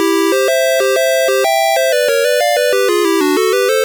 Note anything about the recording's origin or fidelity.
Source Recorded from the Sharp X1 version.